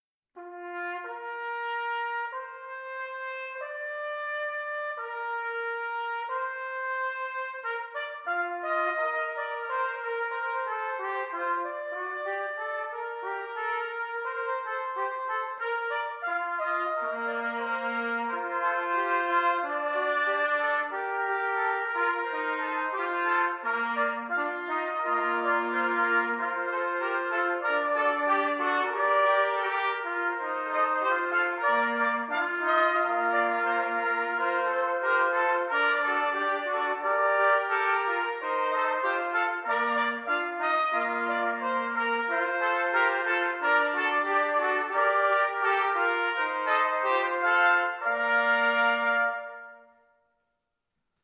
Classical (View more Classical Choir Music)